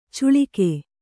♪ cuḷike